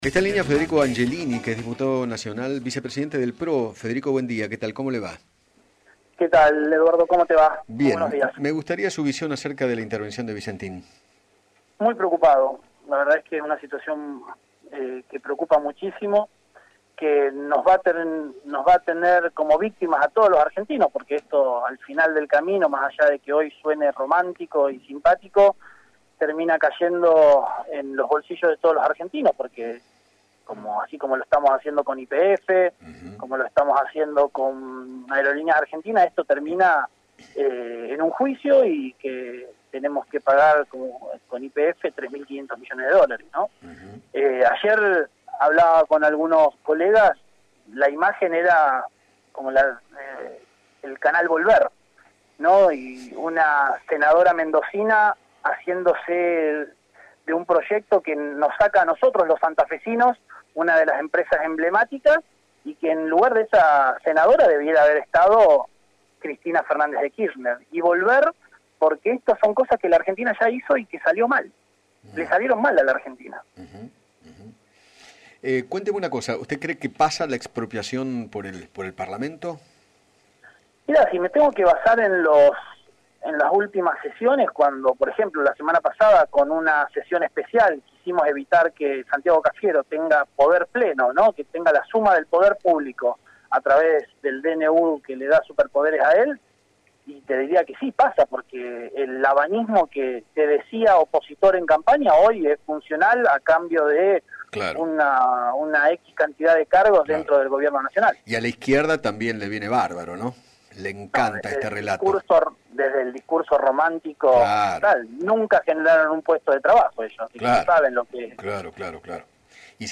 Federico Angelini, diputado Nacional de Juntos Por el Cambio y vicepresidente del Pro, dialogó con Eduardo Feinmann sobre la intervención de Vicentín y comparó a Alberto Fernández con Hugo Chávez al referirse de “soberanía alimentaria”.